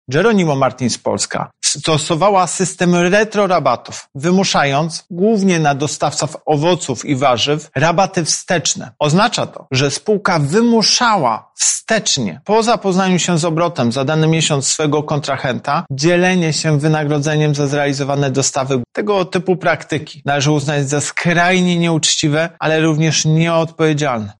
W przypadku Jeronimo Martins to się jednak nie stało – tłumaczy Prezes UOKiK, Tomasz Chróstny: